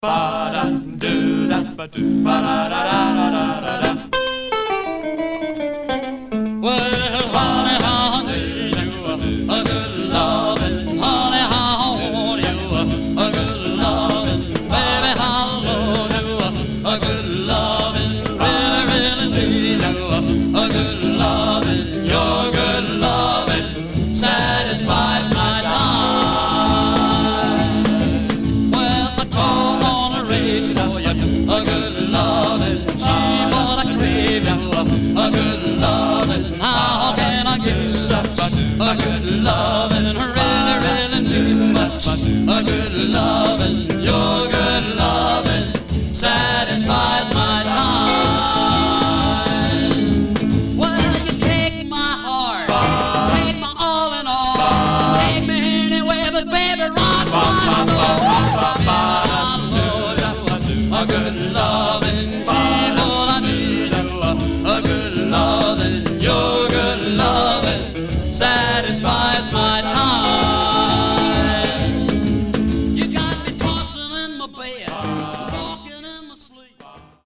Stereo/mono Mono
Rockabilly Condition